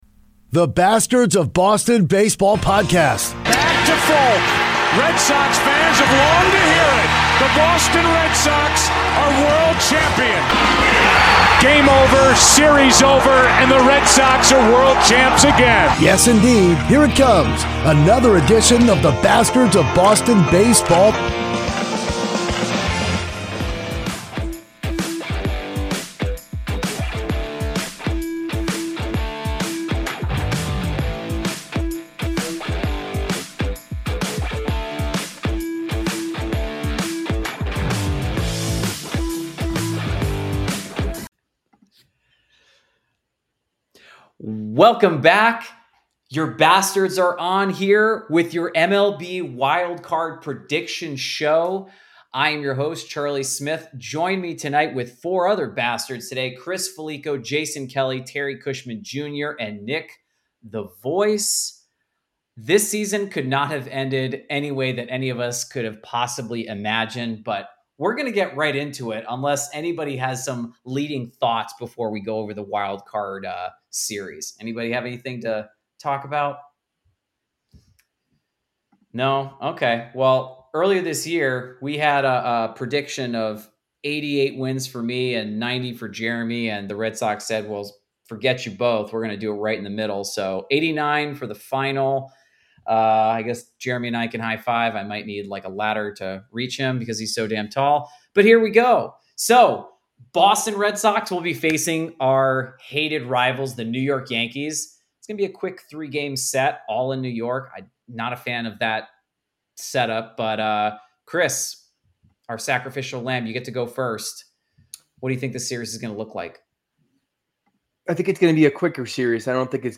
Five hosts predict each matchup! Are there any upset specials?